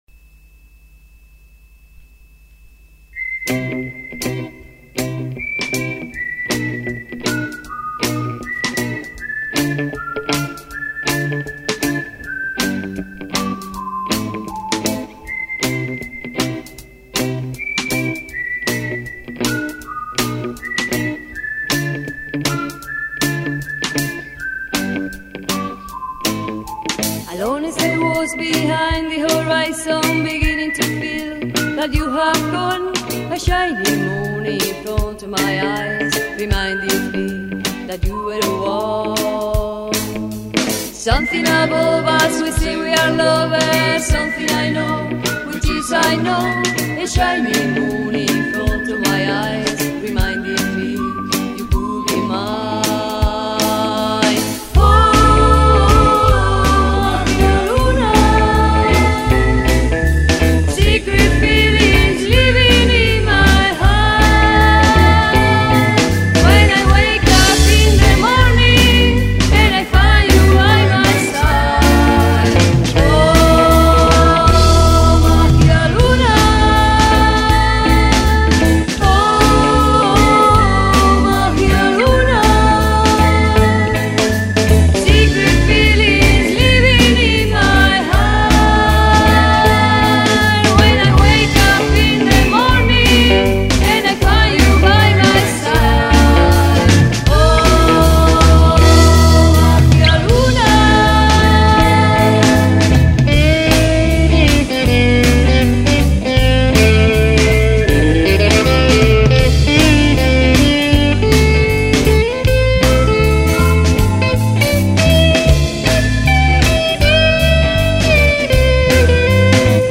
Guitarra eléctrica, armónica y voz
Guitarra acústica y voz
Batería
Guitarra bajo
Teclados
Voz y percusión